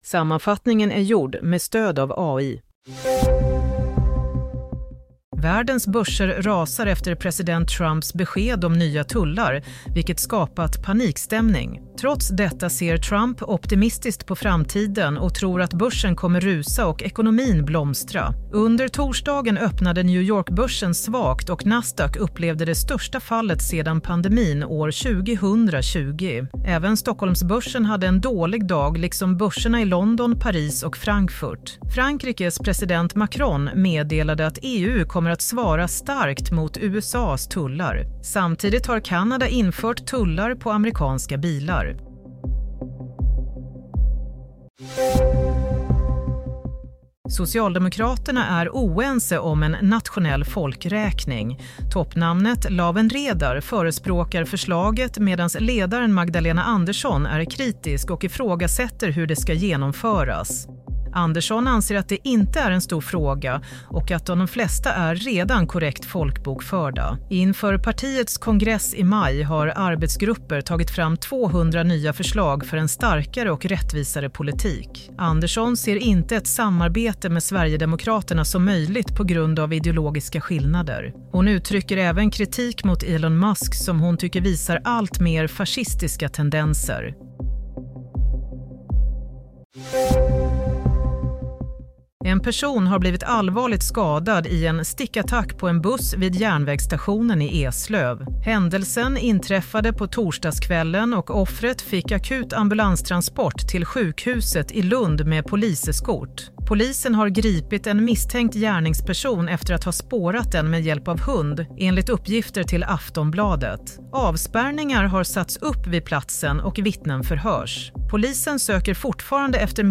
Play - Nyhetssammanfattning – 3 april 22:00
Sammanfattningen av följande nyheter är gjord med stöd av AI. - Trump: Börsen kommer att rusa - Magdalena Andersson kritisk till toppnamnets förslag - Mordförsök på buss – en gripen Broadcast on: 03 Apr 2025